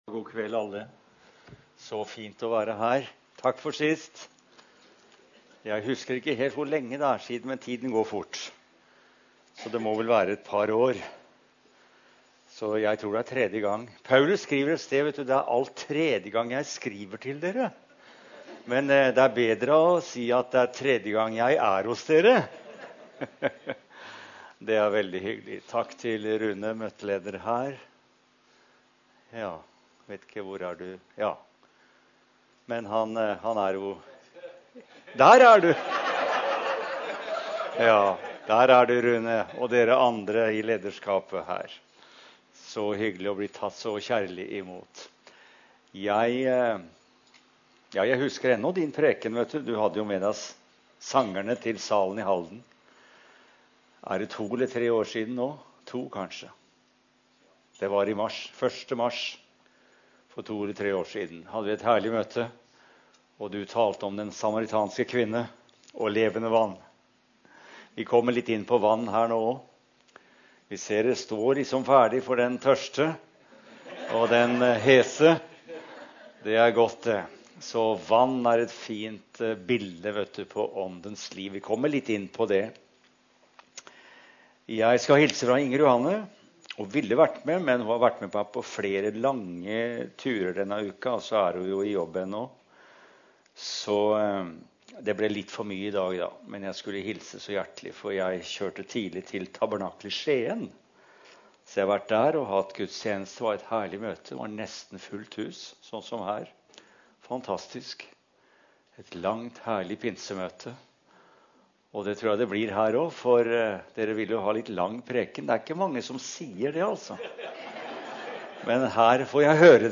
Søndagsgudstjeneste 2018